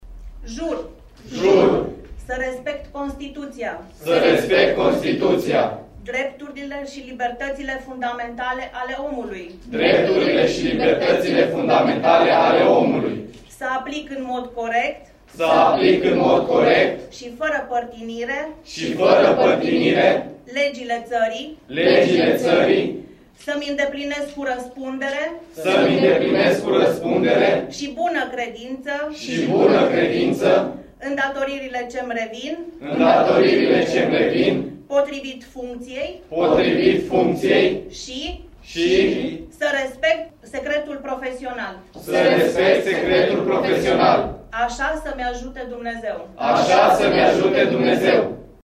Momentul depunerii jurământului a fost unul foarte emoţionant pentru tineri dar şi pentru familiile lor.